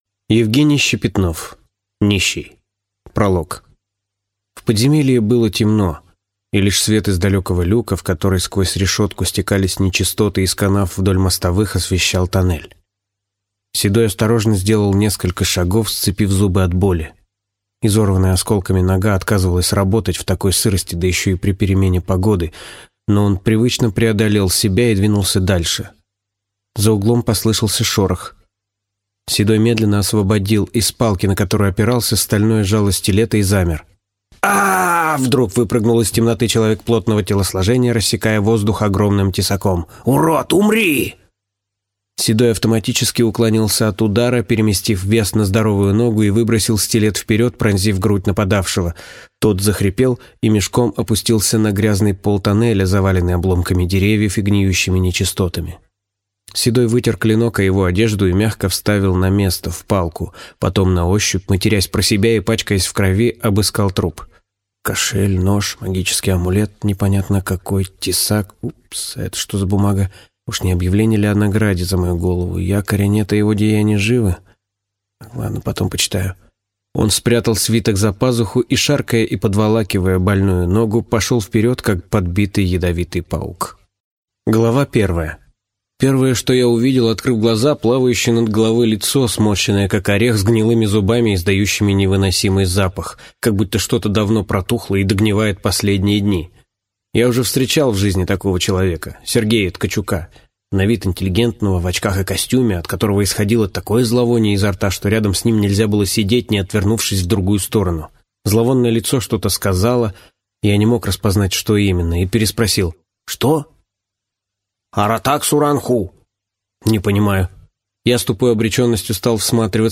Аудиокнига Нищий | Библиотека аудиокниг